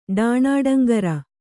♪ ḍāṇāḍaŋgara